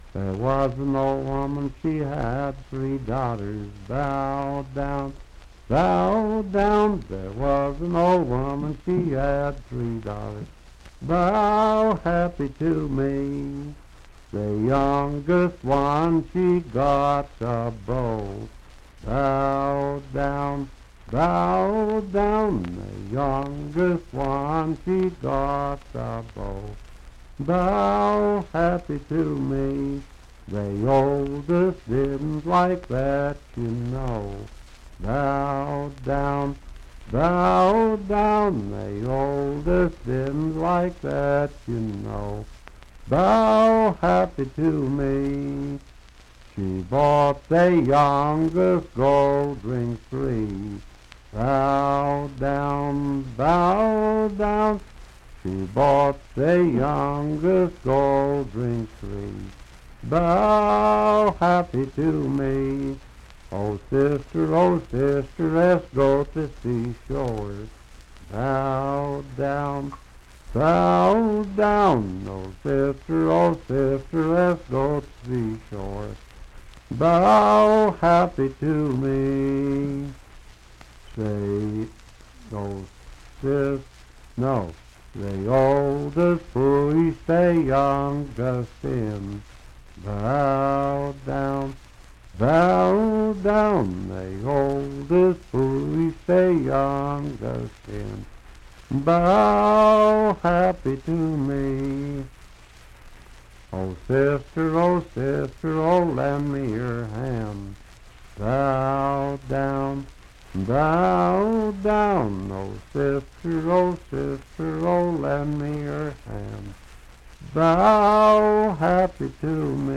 Unaccompanied vocal music
Verse-refrain 13(4w/R).
Voice (sung)
Fairview (Marion County, W. Va.), Marion County (W. Va.)